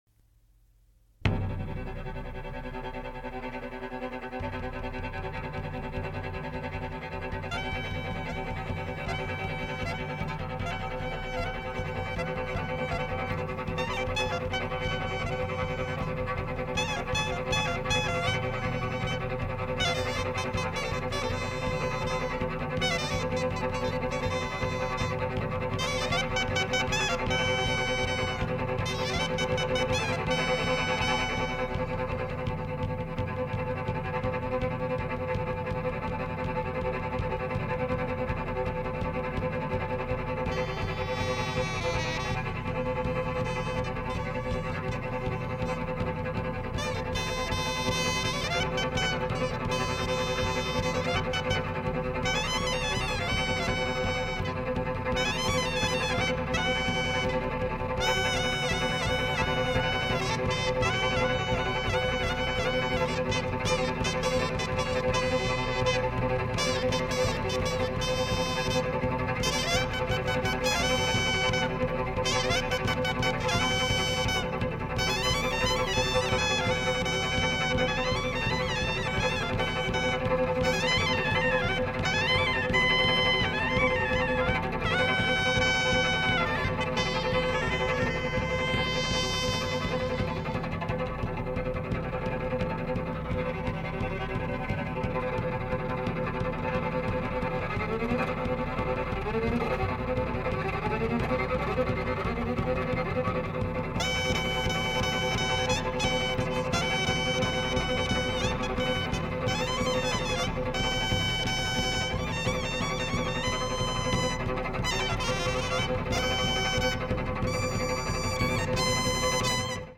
民族音楽や前衛音楽の香りをそこはかとなく漂わせたスピリチュアルなサウンドが見事な73年の怪作！